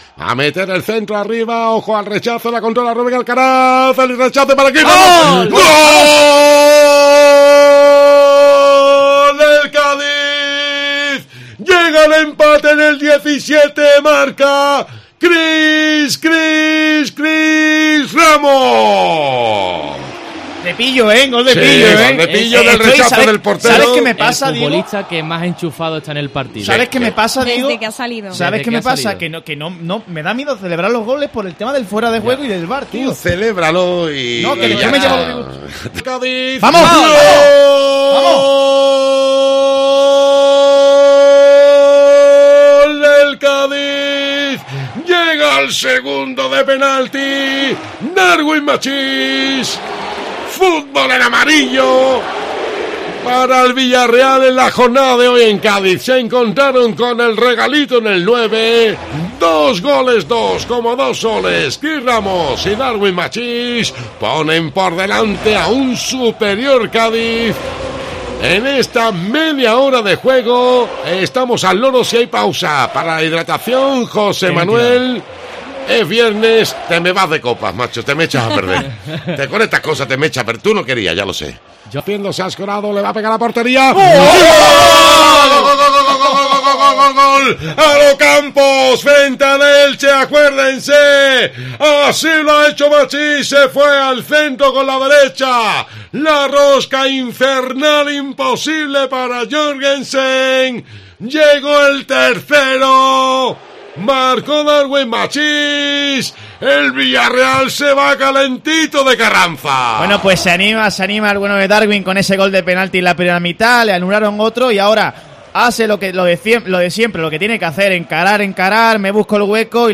Así sonaron los goles del Cádiz ante el Villarreal en COPE Cádiz